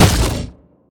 biter-roar-behemoth-6.ogg